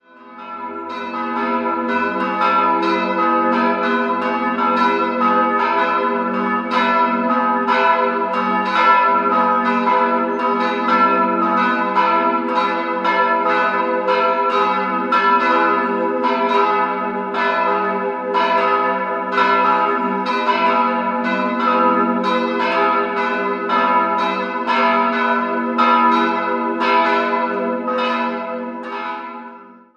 Ziegetsdorf, Pfarrkirche St. Josef Der Ortsteil Ziegetsdorf liegt im Südwesten der Stadt Regensburg, die St. Josefskirche steht ganz in der Nähe der Autobahn.
Das großzügige Innere ist sehr schlicht gehalten. 3-stimmiges E-Dur-Geläute: e'-gis'-h' Sowohl die große Glocke von 1899, als auch die mittlere aus dem Jahr 1877 stammen aus der Gießerei Spannagl in Regensburg.